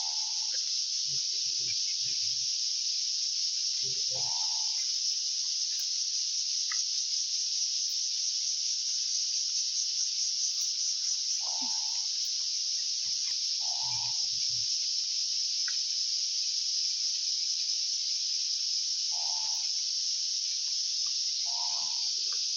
Odontophrynus asper
Ordem: Anura
Localidade ou área protegida: Derrubadas
Condição: Selvagem
Certeza: Gravado Vocal